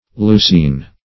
Leucine \Leu"cine\ (l[=oo]"s[=e]n), Leucin